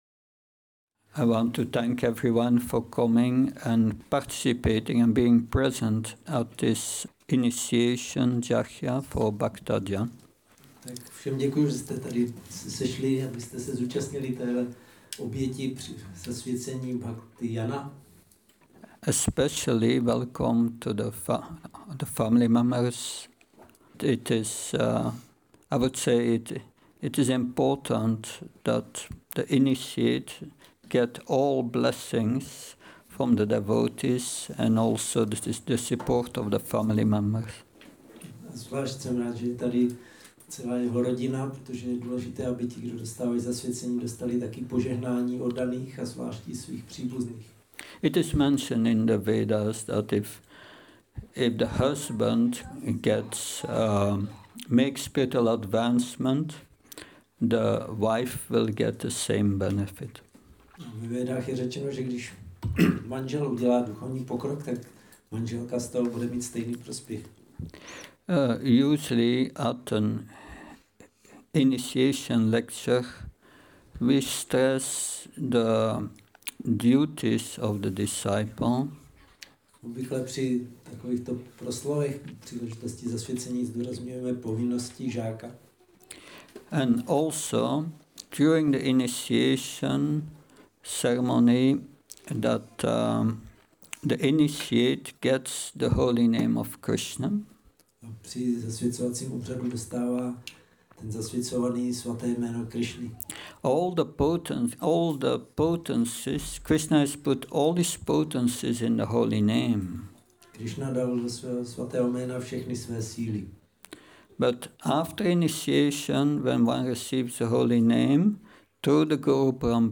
Zasvěcení